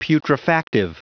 Prononciation du mot putrefactive en anglais (fichier audio)
Prononciation du mot : putrefactive